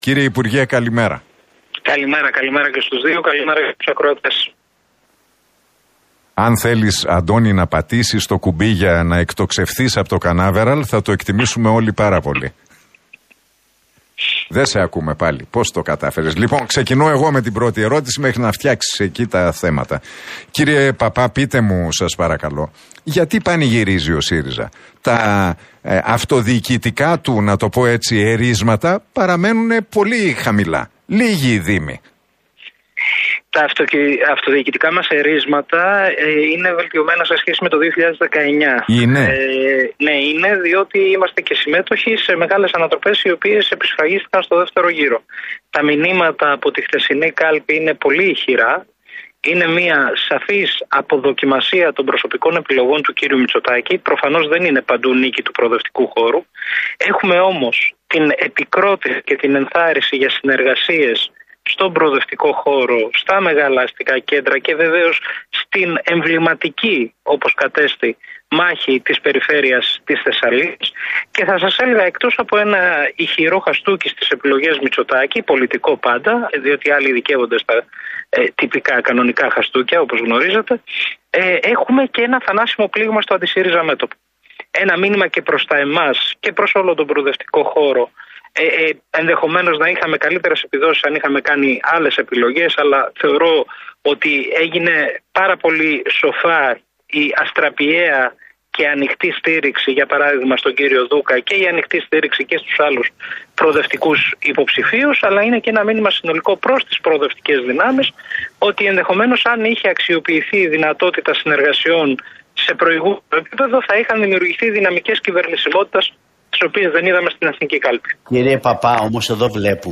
Παππάς στον Realfm 97,8: Ισχυρό κύμα αποδοκιμασίας των κυβερνητικών εκλεκτών